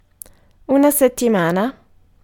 Ääntäminen
IPA : /wiːk/ US : IPA : [wiːk] UK